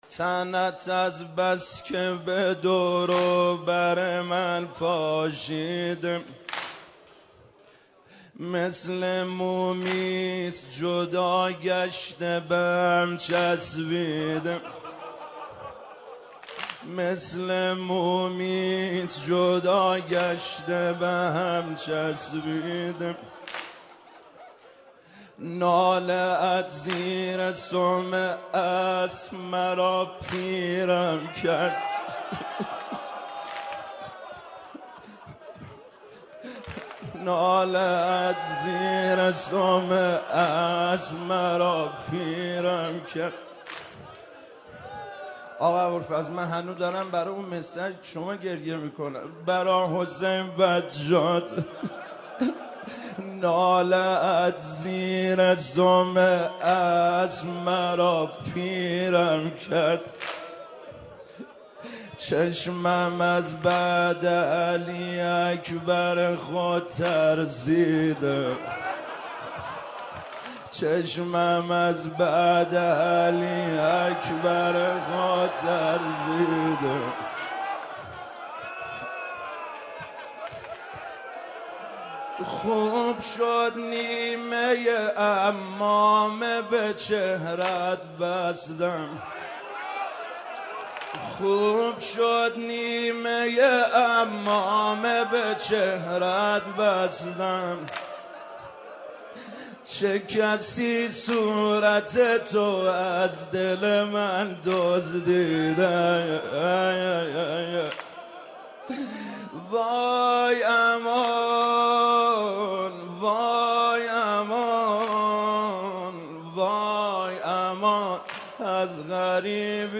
در شب ششم محرم صورت گرفت؛
مداحی